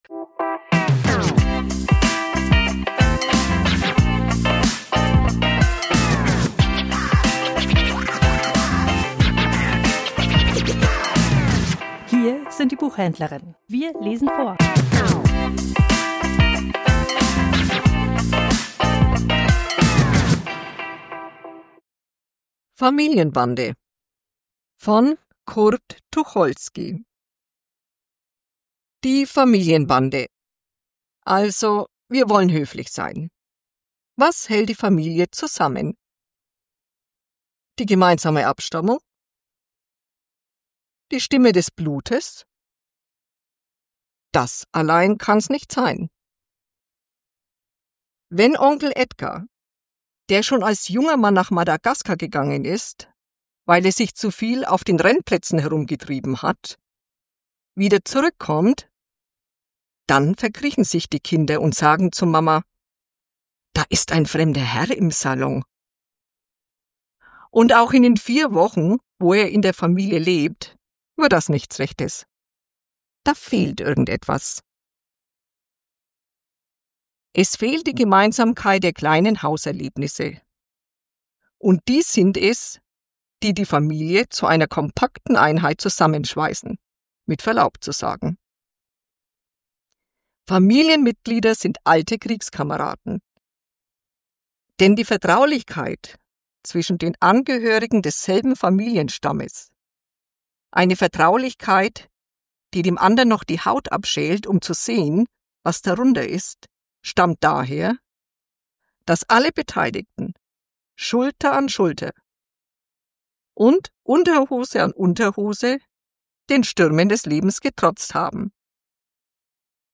Vorgelesen: Familienbande